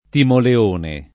vai all'elenco alfabetico delle voci ingrandisci il carattere 100% rimpicciolisci il carattere stampa invia tramite posta elettronica codividi su Facebook Timoleone [ timole 1 ne ] o Timoleonte [ timole 1 nte ] pers. m. stor.